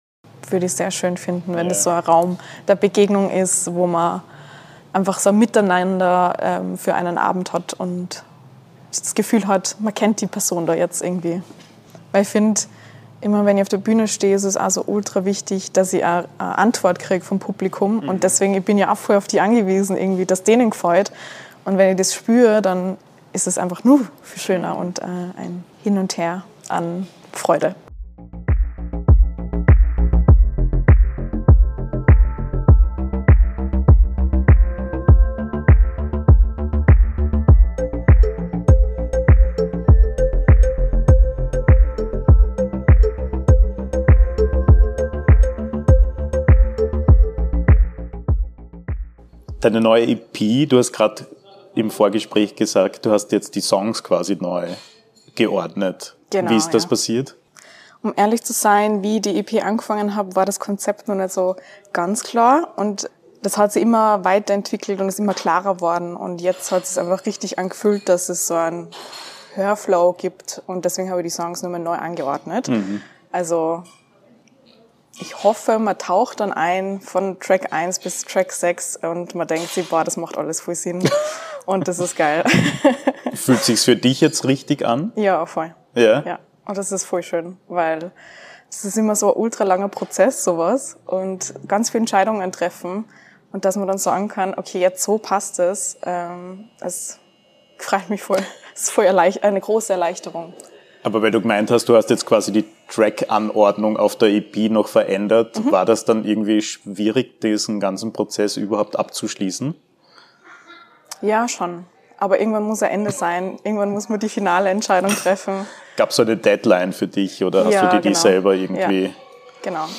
Ein Gespräch über Widersprüche, Entwicklung und den Versuch, sich selbst und andere besser zu verstehen.